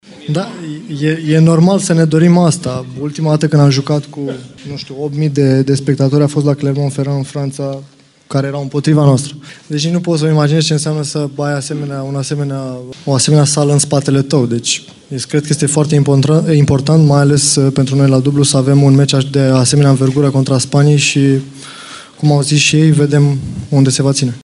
Florin Mergea vorbeşte despre importanţa publicului la meciurile de Cupa Davis: